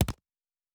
Fantasy Interface Sounds
UI Tight 05.wav